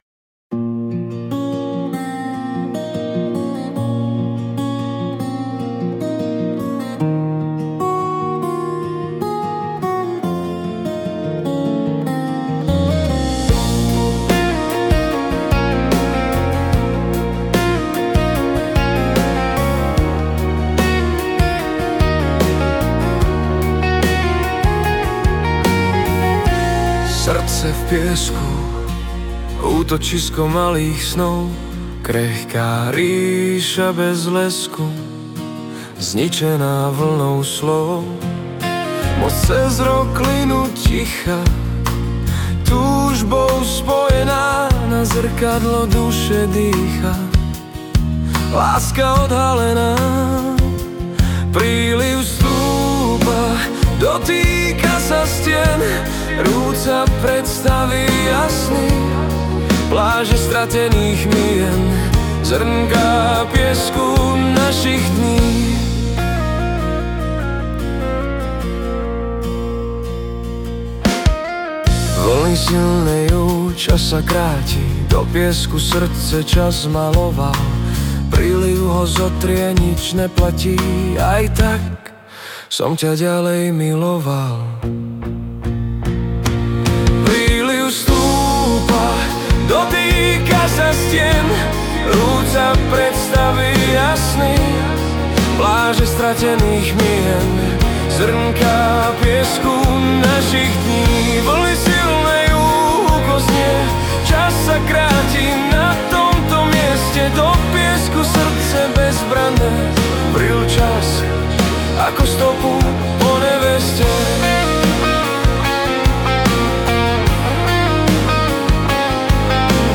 Hudba a spev AI
Balady, romance » Láska